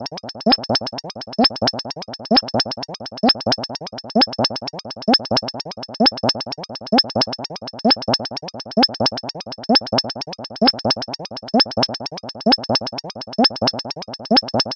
Hip Hop Drums » 70
标签： pack loop trip 130bpm hop drumloop glitch beat drum hip
声道立体声